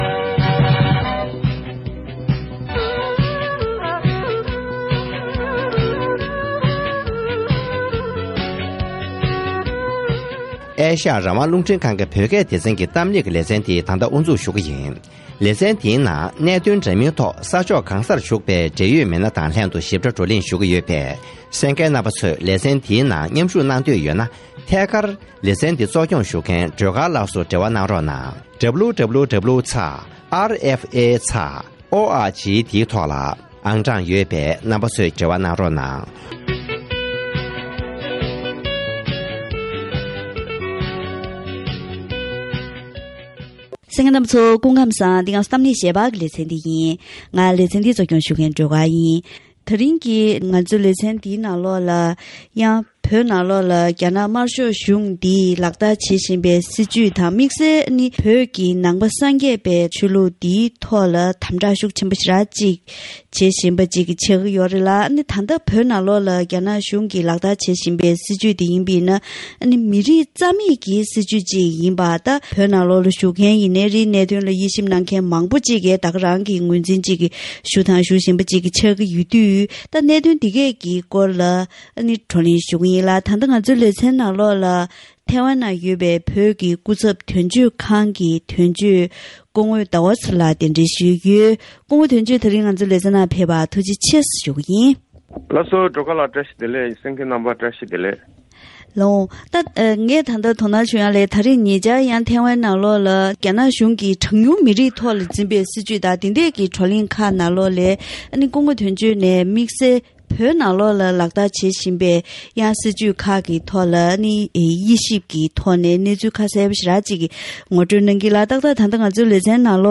གཏམ་གླེང་